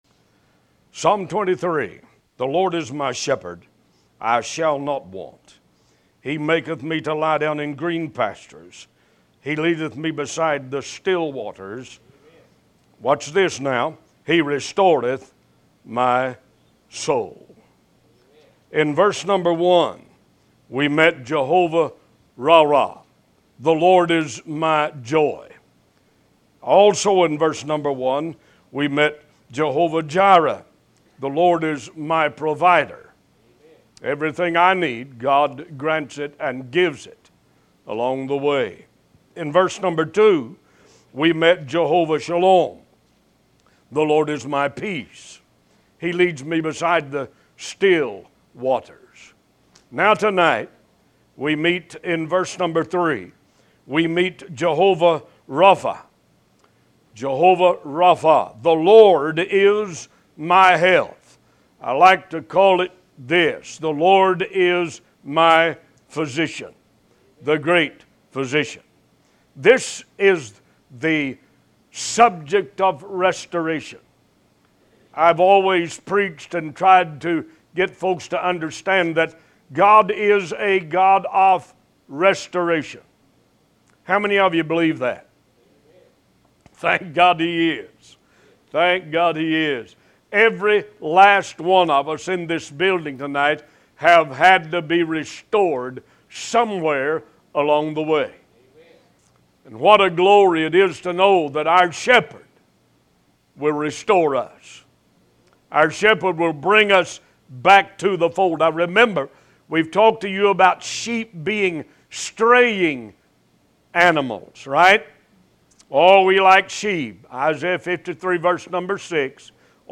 New Sermons published every Sunday and Wednesday at 11:30 AM EST